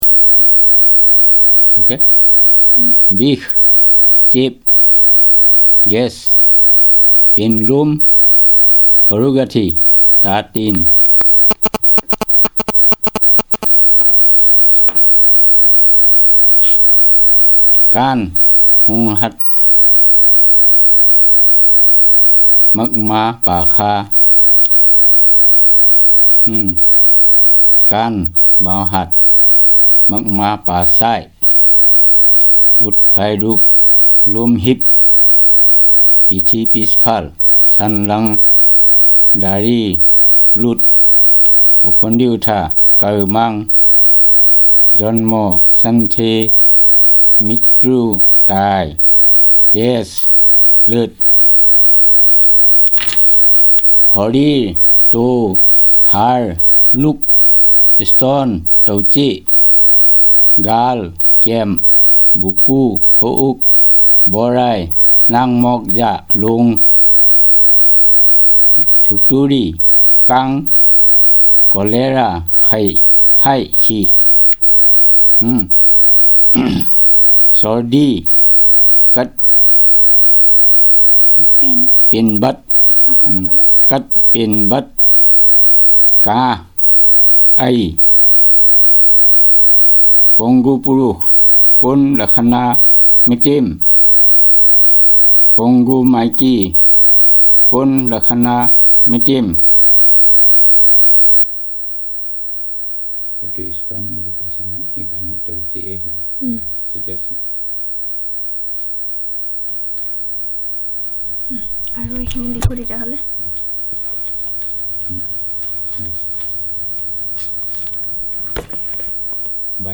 NotesThis is an elicitation of words about human body parts, bodily functions using the questionnaire